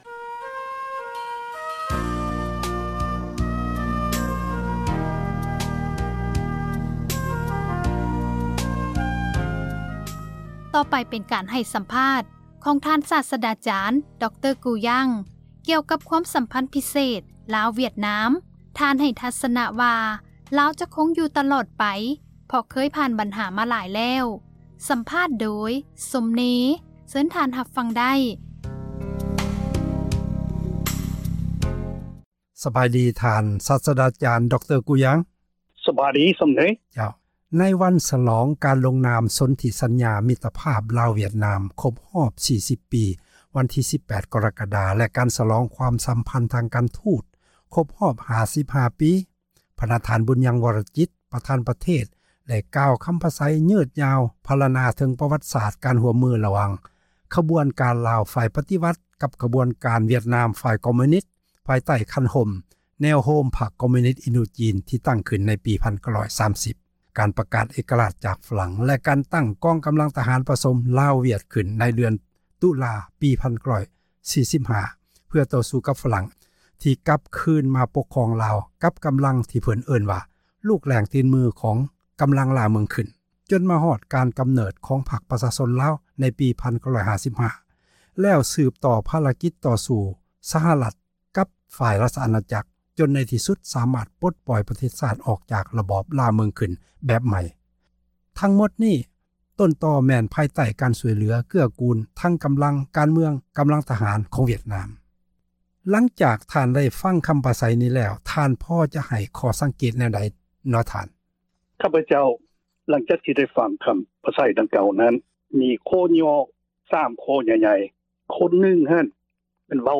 ການສັມພາດ